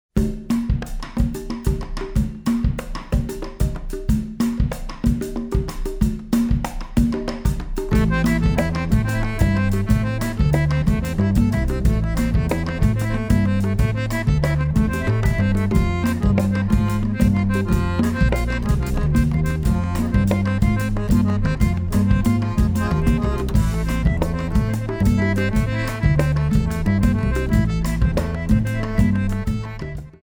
congas, marimba, bodhran;